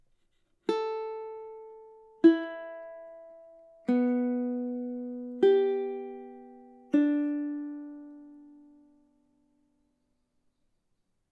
La contra se afina de una manera más grave:
El sonido de la contra es una cuarta justa más grave que el del timple. Esto se traduce en un tono más dulce, amable y profundo.
Así suena la CONTRA cuerdas al aire
Cuerdas-al-aire-CONTRA.mp3